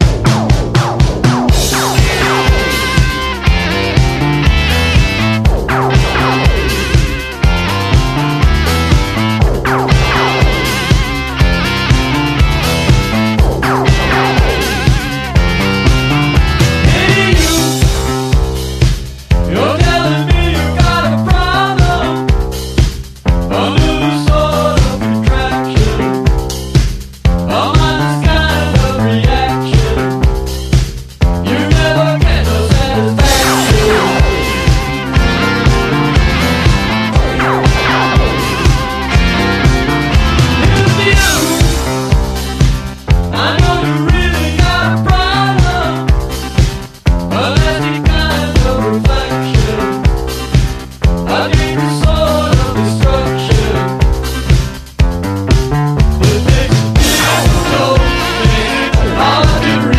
TECHNO POP / ELE POP / SYNTH POP
テクノ・ポップ・ミーツ・スウィングな傑作盤！